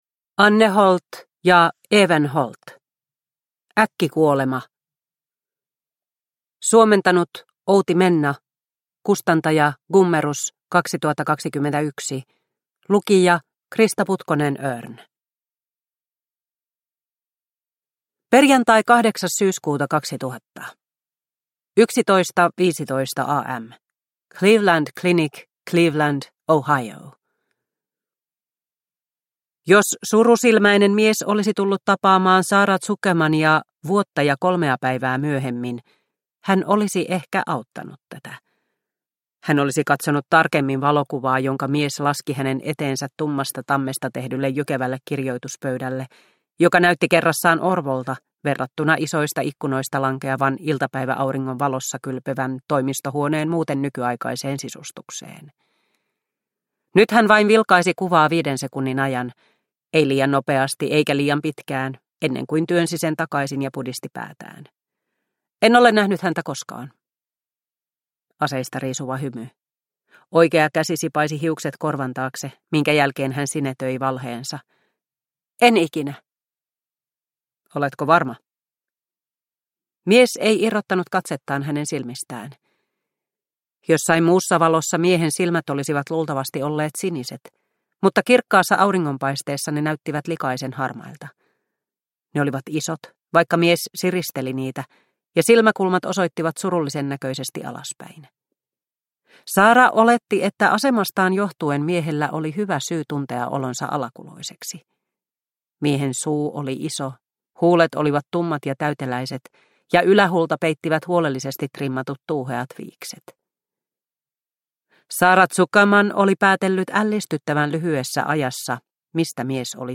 Äkkikuolema – Ljudbok – Laddas ner